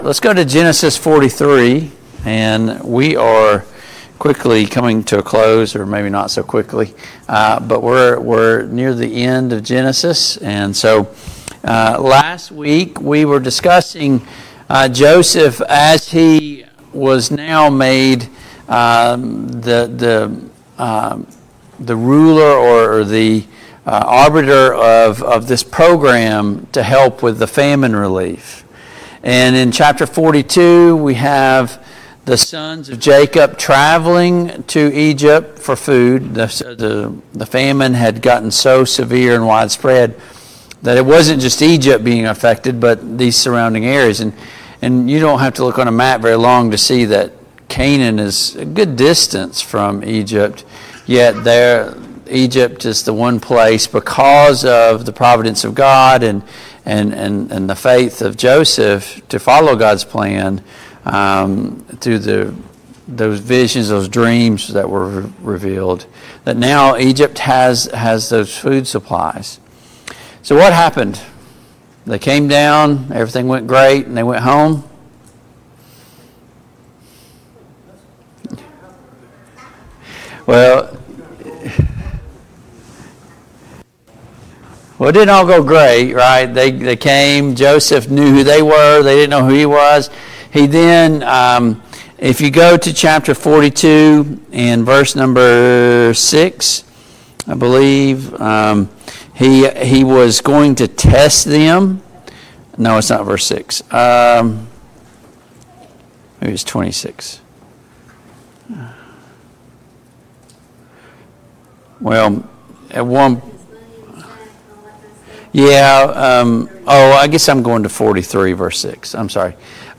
Family Bible Hour